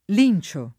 vai all'elenco alfabetico delle voci ingrandisci il carattere 100% rimpicciolisci il carattere stampa invia tramite posta elettronica codividi su Facebook linciare v.; lincio [ l & n © o ], ‑ci — fut. lincerò [ lin © er 0+ ]